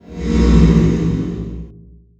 Magic_SpellCloak04.wav